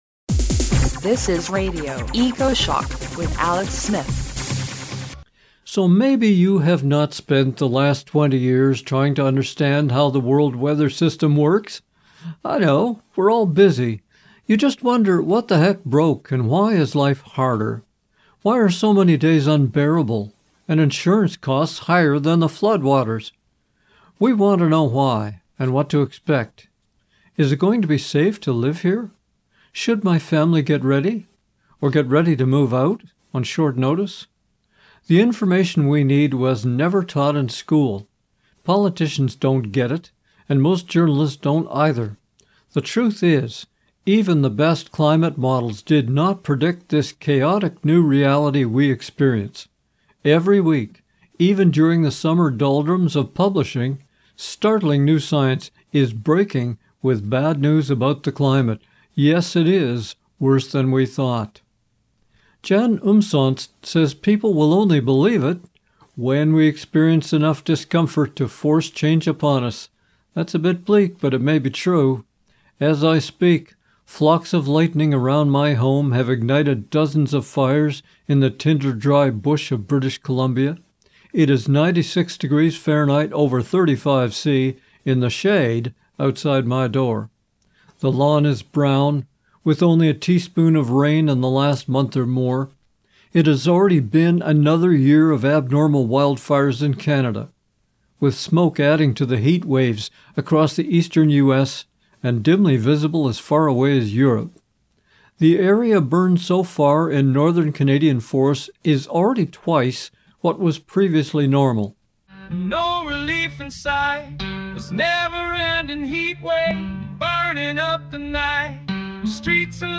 Extreme heat and heavy rains amplify one another: a new conversation